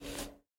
cigarette_restart.ogg